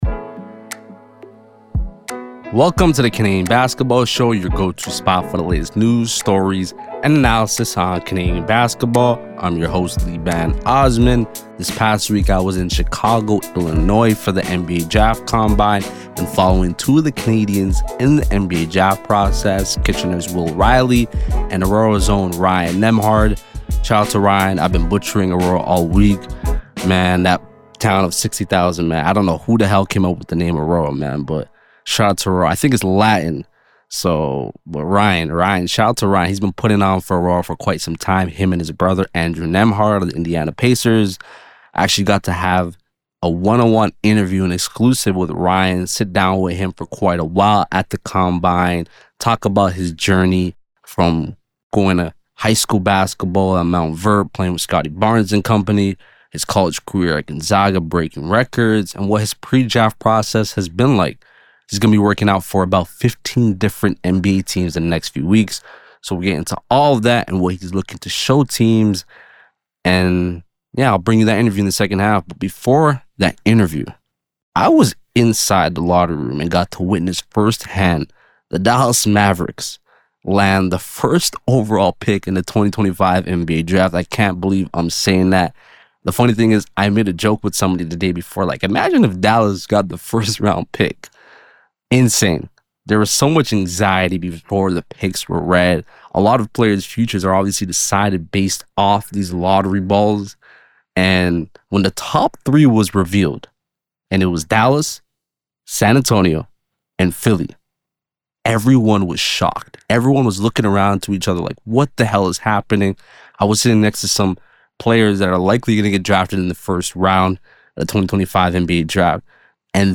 12:23: Interview